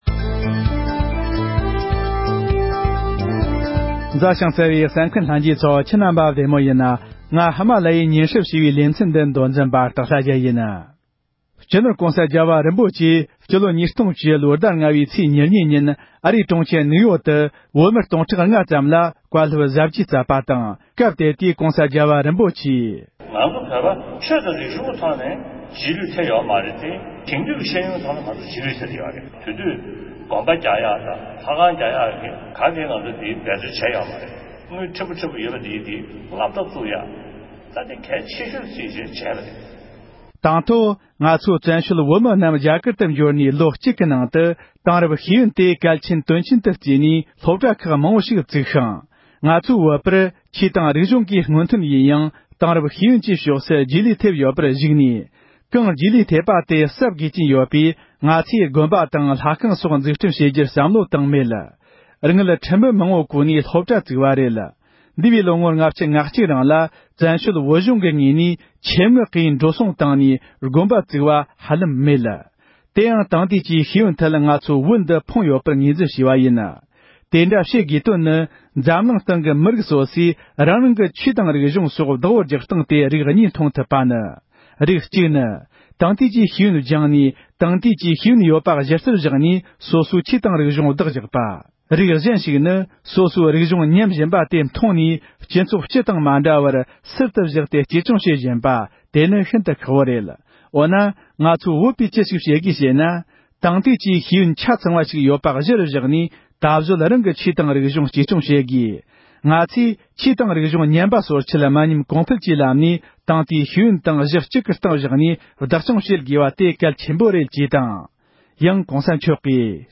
༸གོང་ས་མཆོག་གིས་ཨ་རིའི་གྲོང་ཁྱེར་ནིའུ་ཡོརྐ་ཏུ་བོད་མི་ལྔ་སྟོང་བརྒལ་བར་བོད་ཀྱི་བདེན་མཐའ་གསལ་རྒྱུའི་ལས་དོན་སྐོར་བཀའ་གནང་བ།